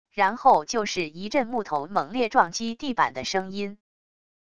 然后就是一阵木头猛烈撞击地板的声音wav音频